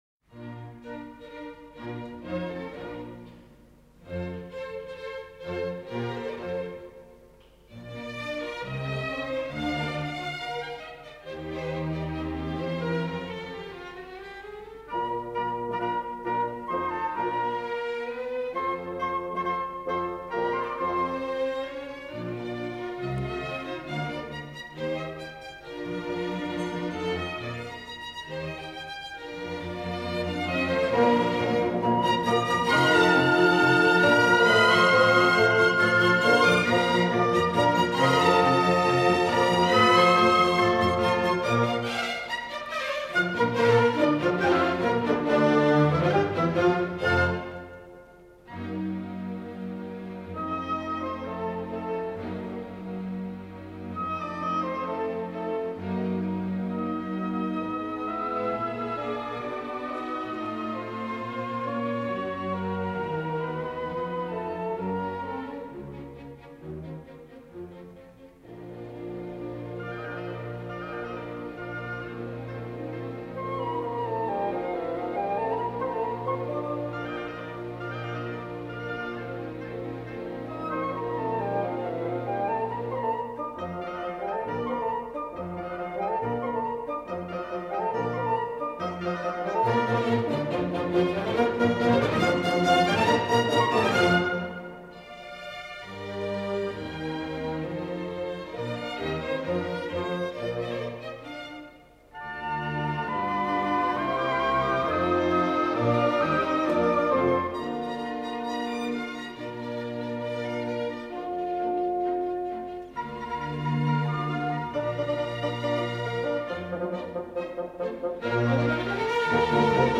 Over to Cologne this weekend for a historic performance by the West German Radio Symphony, conducted by Joseph Keilbert and featuring Ingrid Haebler, piano.